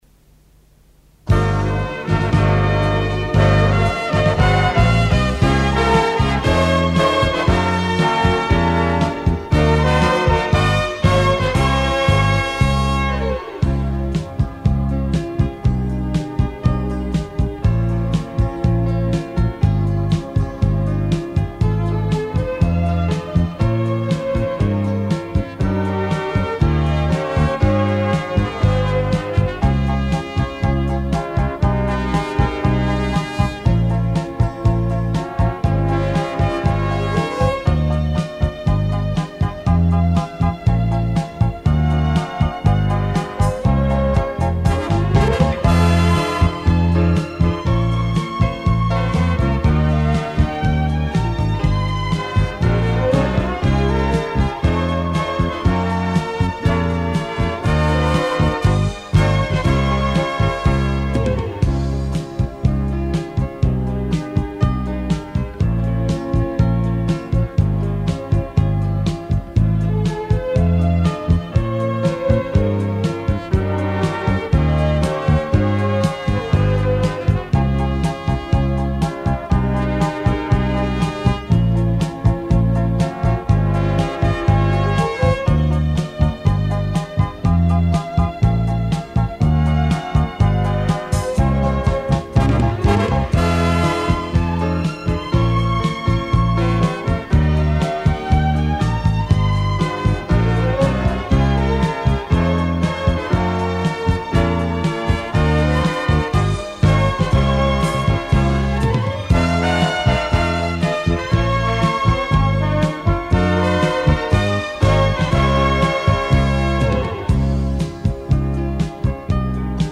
歌なし